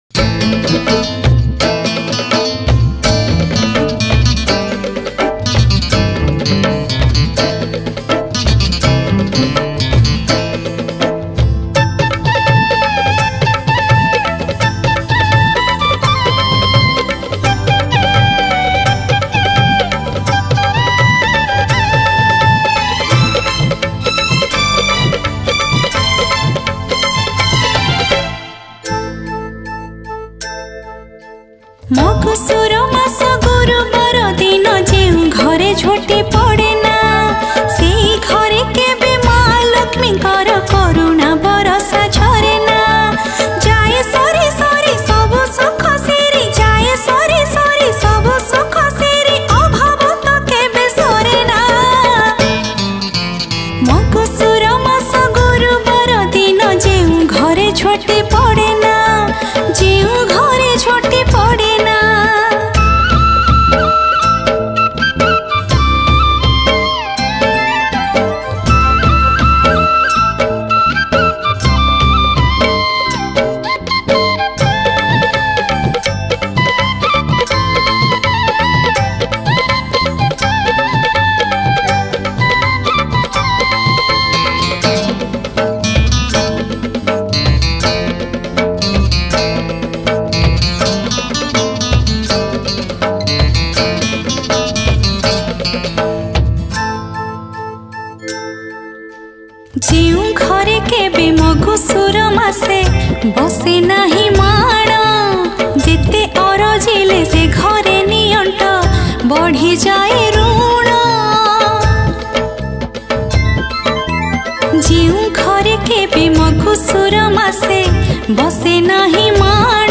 Category : Manabasa Gurubara Bhajan